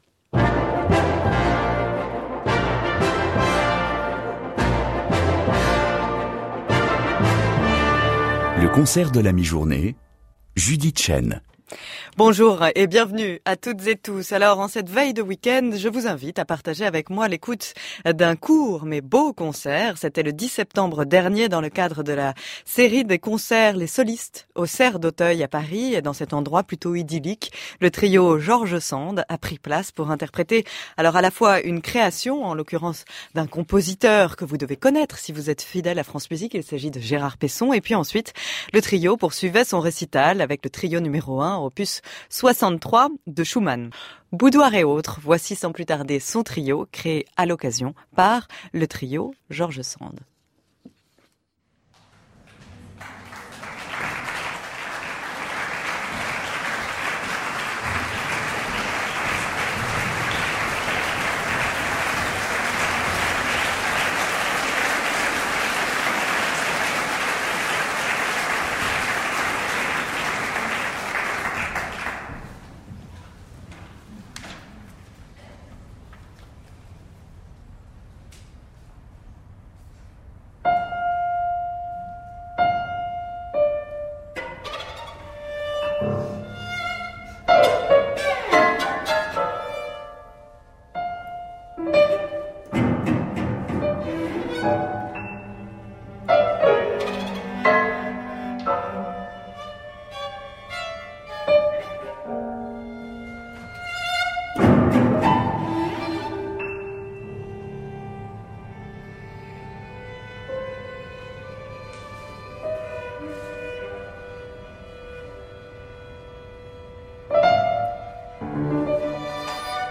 in concert
only two works (and an encore)
live and direct for their Afternoon Concerts series